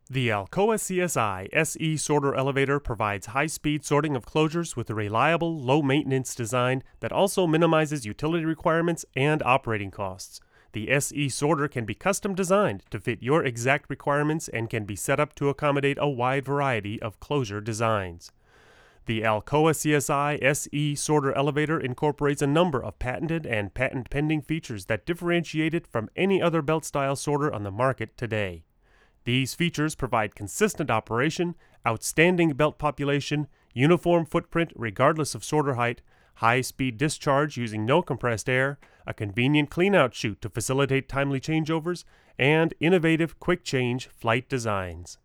These are raw recordings - I've done nothing with EQ or compression on these files.
I had the mics side-by-side with the capsules as close together as I could get them and recorded both mics simultaneously.
I recorded samples with a Mackie VLZ mixer as well as a Sebatron VMP preamp.
Oh, and stick a pencil in front of the mic you end up using - there are plosives!
nt2000_mackieVLZ.wav